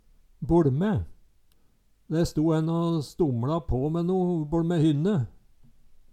DIALEKTORD PÅ NORMERT NORSK boLme bortmed Eksempel på bruk De sto en å stomLa på me no boLme hynne Tilleggsopplysningar Kjelde